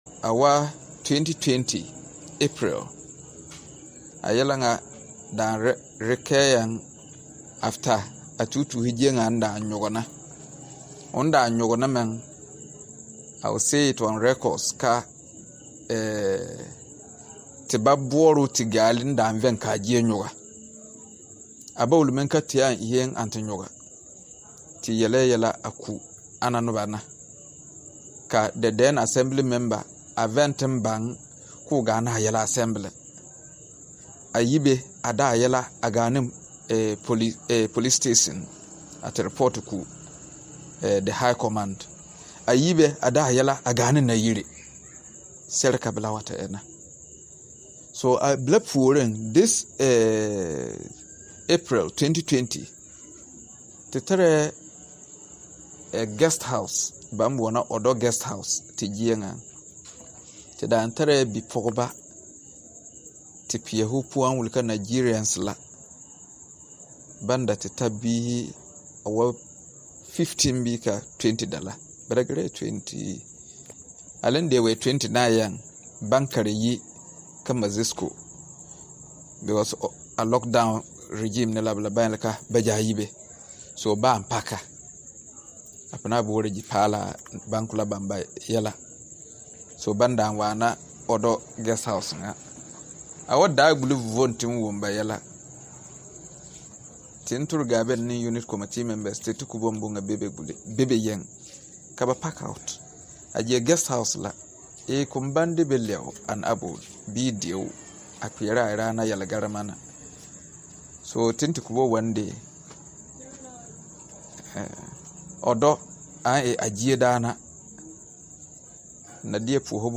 Kpaguri Assembly Man’s Speech